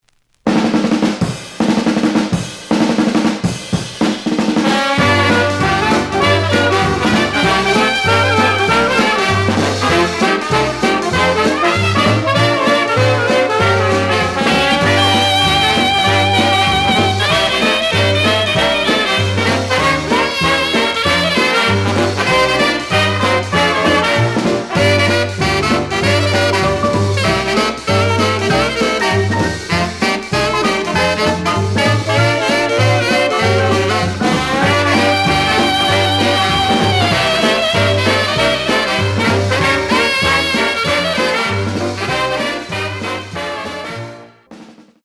The audio sample is recorded from the actual item.
●Genre: Jazz Other